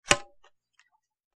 Shell Loaded Into Shotgun With Thump, X3